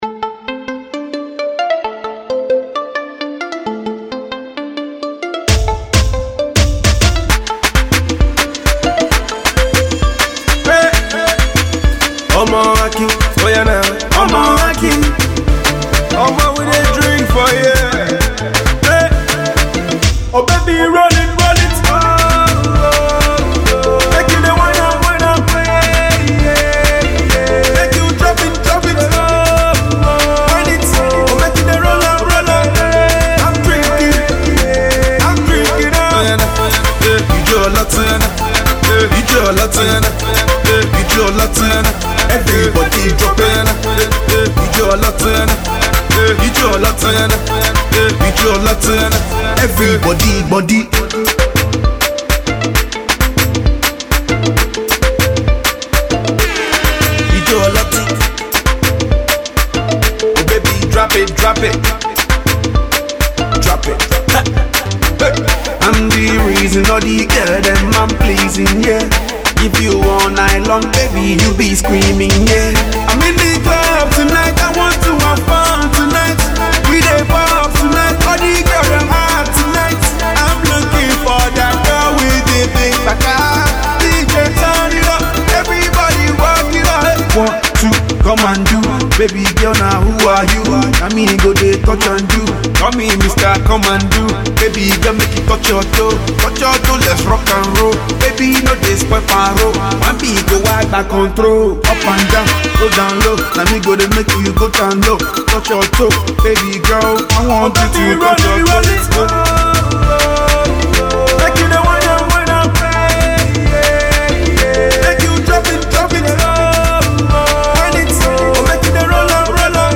sounds like he wants to get us Dancing till Next Year